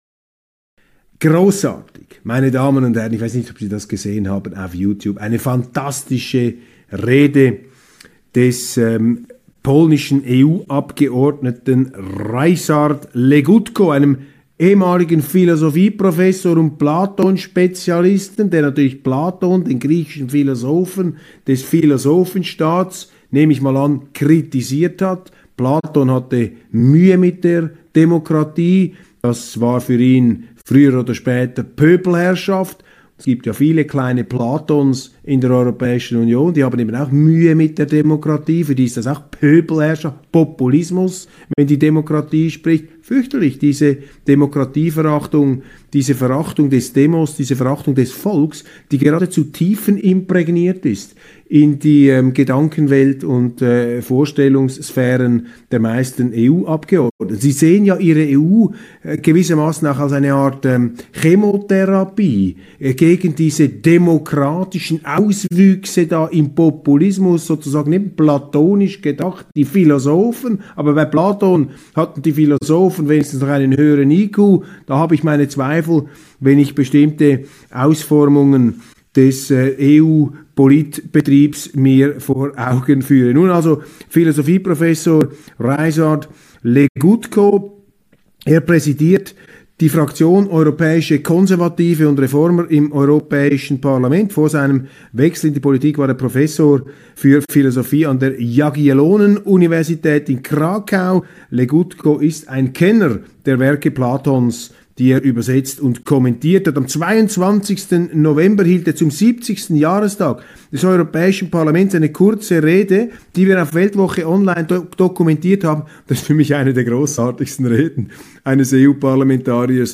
Die Rede Legutkos auf youtube  und die Übersetzung ins Deutsche: